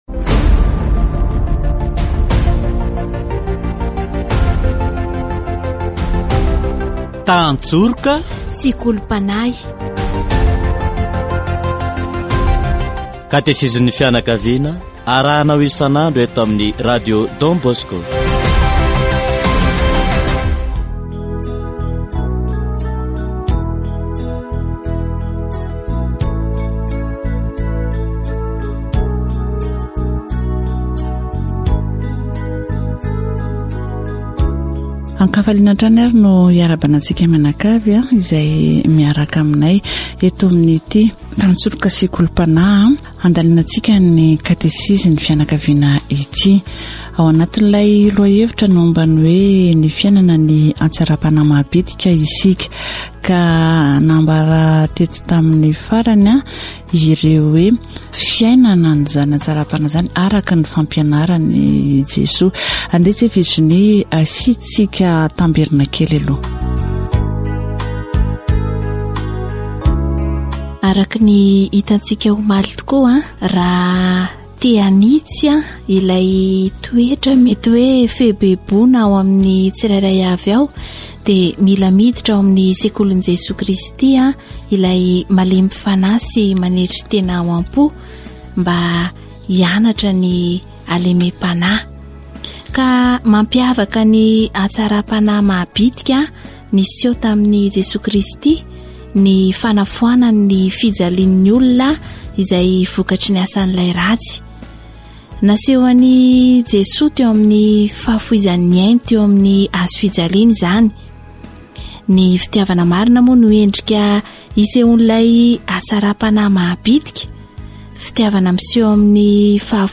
Katesizy momba ny hatsaram-panahy maha-bitika